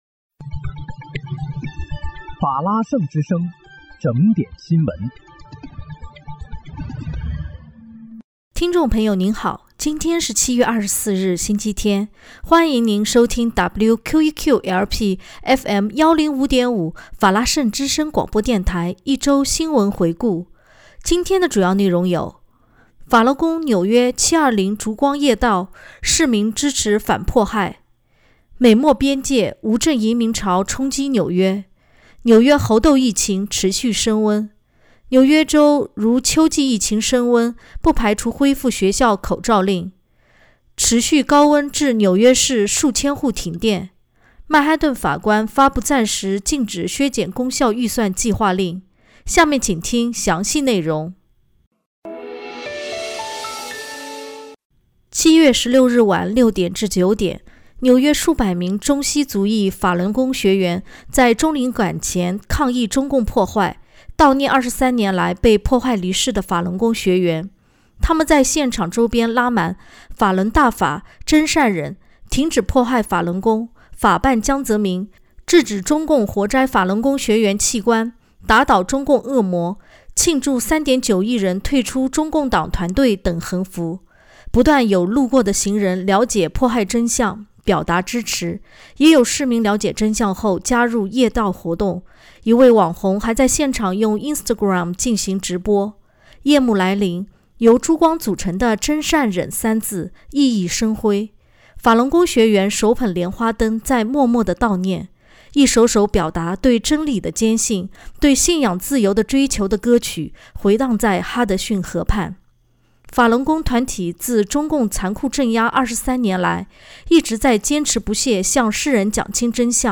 7月24日（星期日）一周新闻回顾